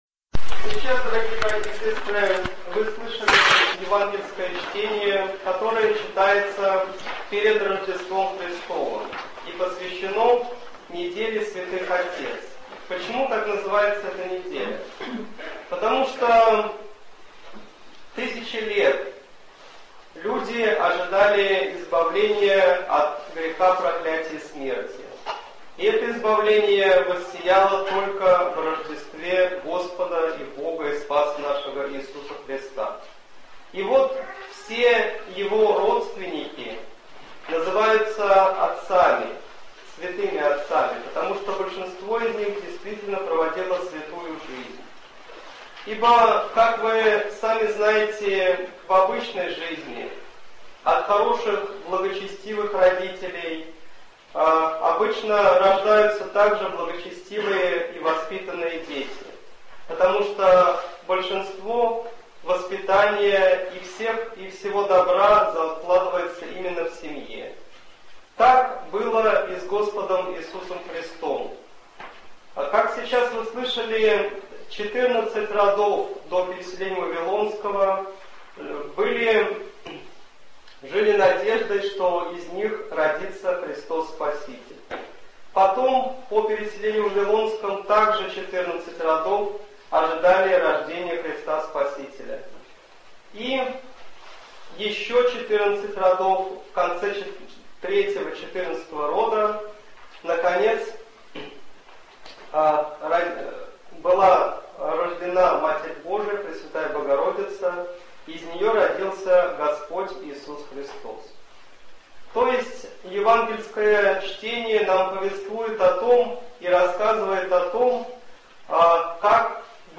Слово в неделю 32-ю по Пятидесятнице, перед Рождеством Христовым, святых отец